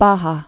(bähä) also Lower California